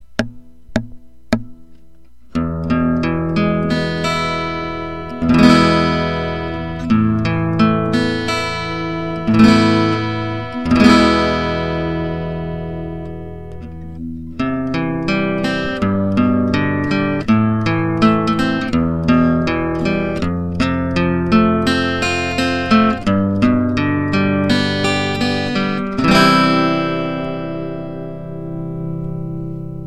・録音はピエゾ→自作のプリ→ミキサ→USBオーディオの変換器でライン入力→パソコン
今回はピエゾ２つなので、より低音を強調できる位置に変更。
全般にレベルがちょっと高かった・・・
ひずみ気味でごめんなさい。
piezo2_6gen_0_1gen_8.mp3